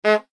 KART_raceStart1.ogg